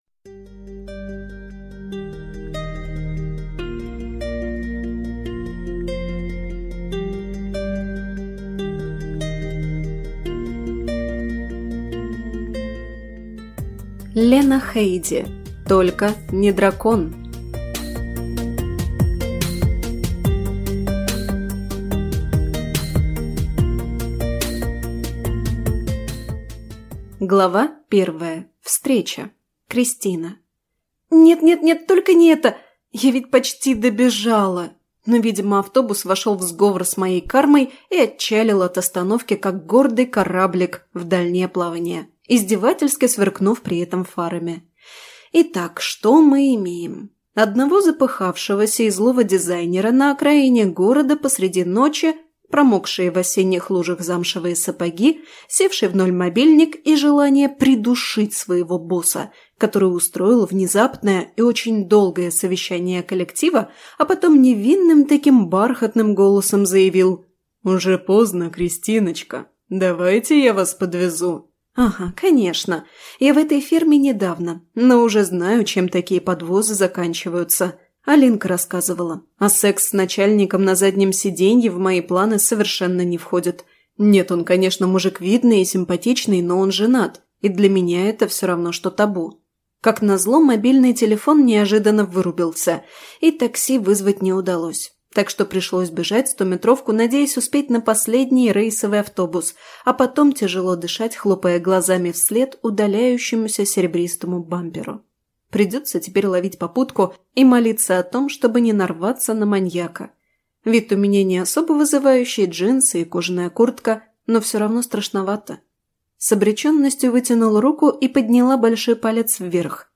Аудиокнига Только не дракон!